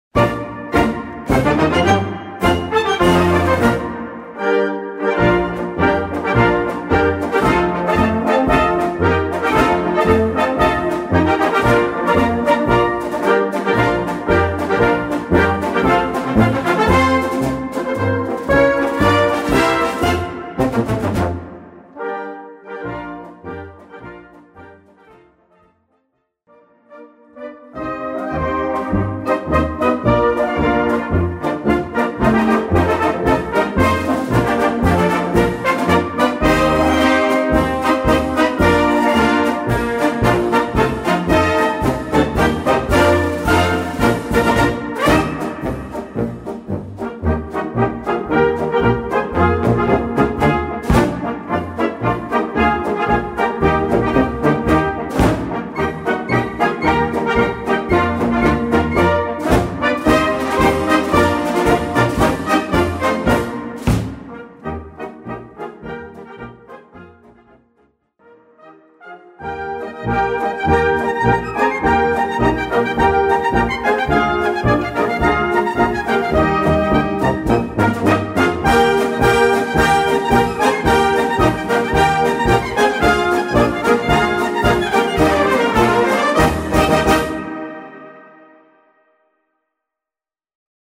Gattung: Marsch für kleine Besetzung
Besetzung: Kleine Blasmusik-Besetzung